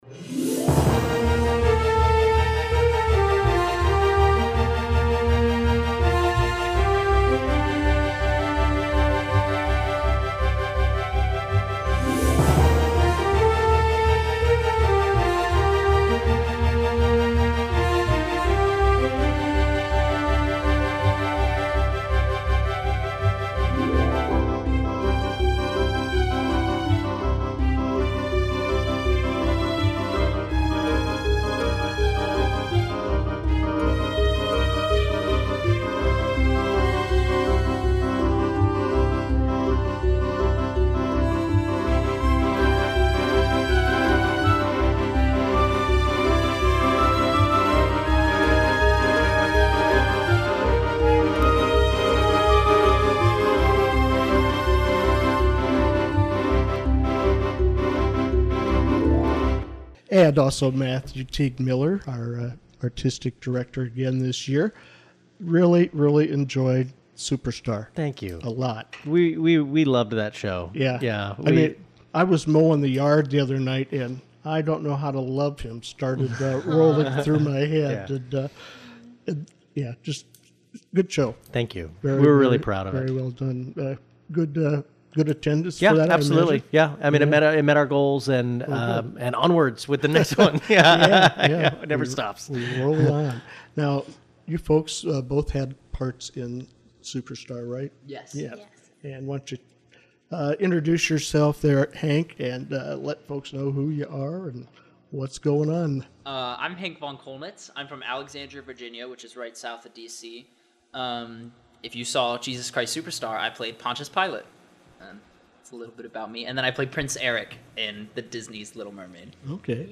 Download link: Little Mermaid Review